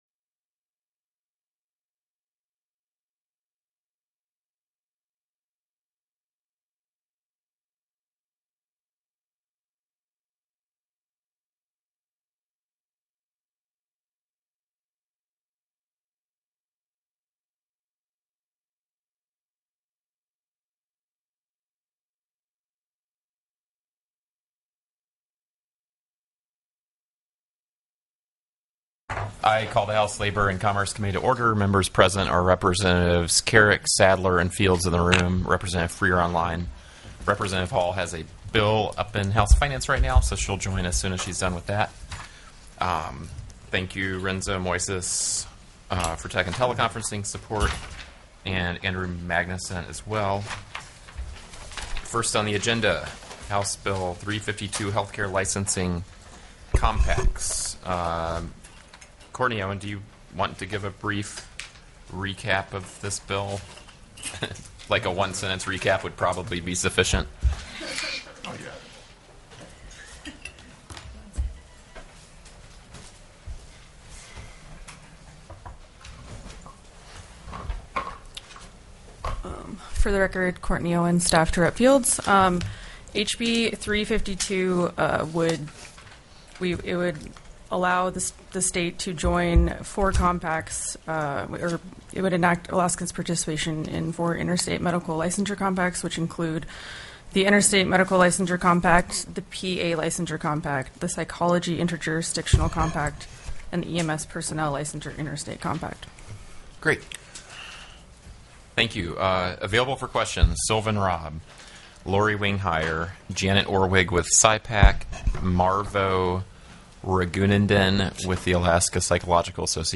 The audio recordings are captured by our records offices as the official record of the meeting and will have more accurate timestamps.
HB 352 HEALTHCARE LICENSING COMPACTS TELECONFERENCED Heard & Held -- Public Testimony --